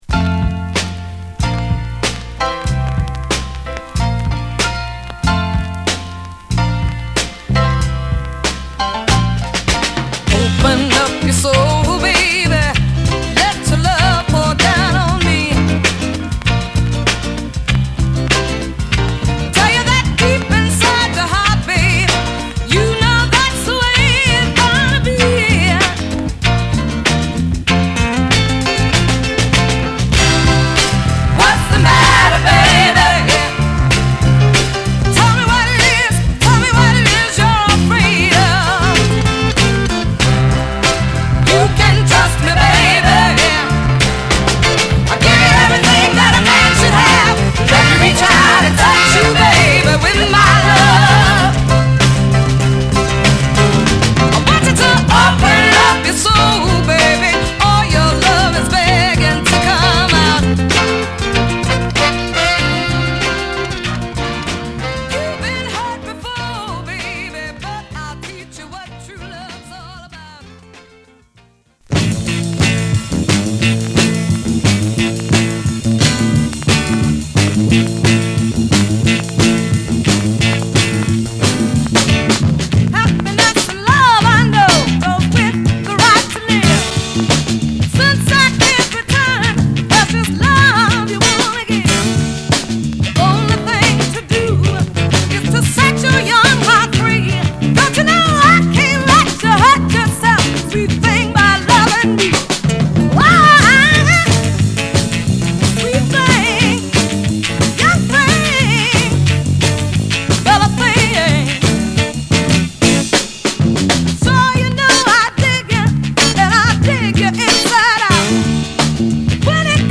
シンプルでファンキーなリズムにピアノやホーンが絡む南部の香りが漂う演奏にファンキーなヴォーカルを乗せるSideA
グルーヴィーなベースが引っ張るSideB